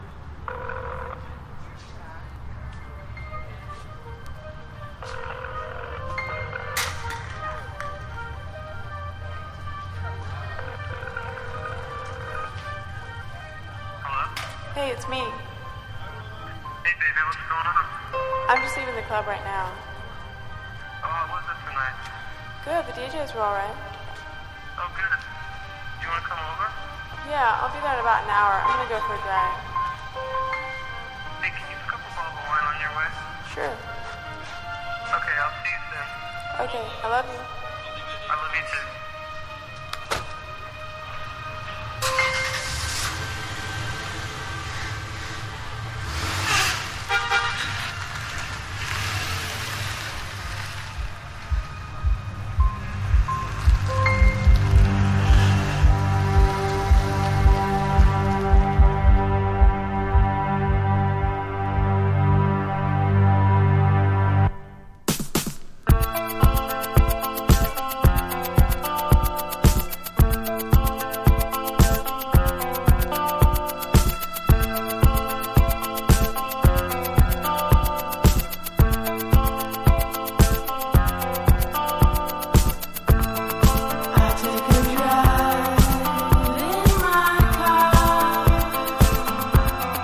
1. 10s ROCK >
ELECTRO POP